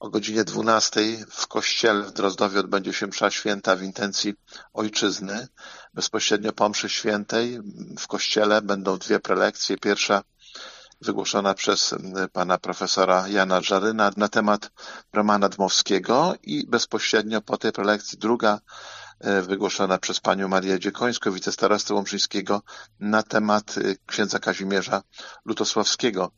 Rozpoczniemy od mszy św.- zaprasza starosta łomżyński, Lech Marek Szabłowski: